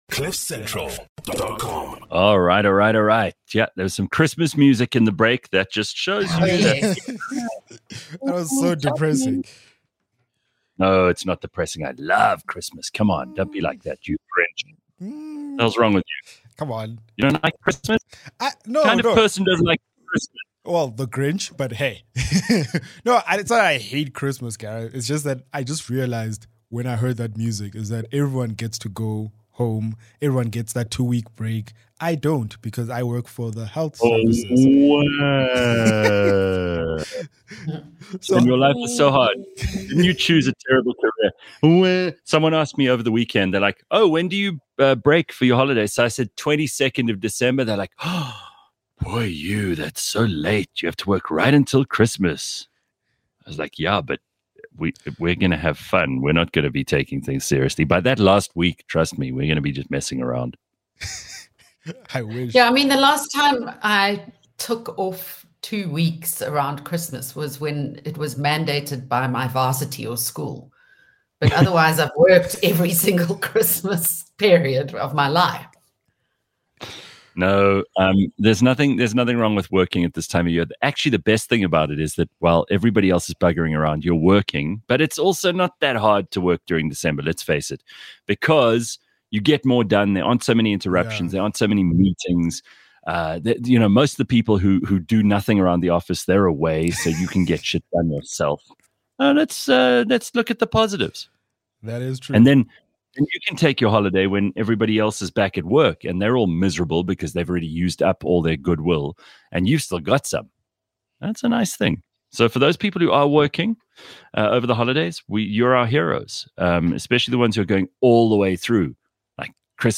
A live podcast show, that’s like a morning radio show, just much better.
Clever, funny, outrageous and sometimes very silly.